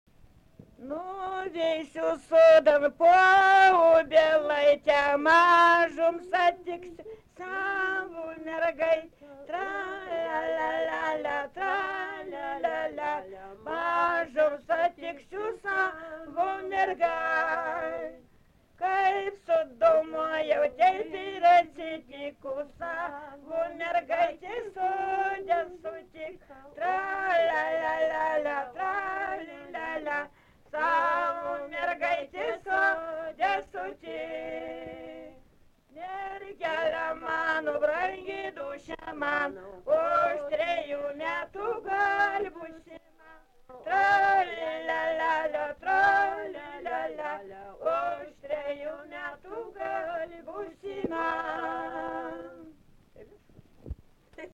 daina
Erdvinė aprėptis Stakliškės
Atlikimo pubūdis vokalinis